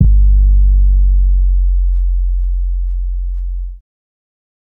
siz 808.wav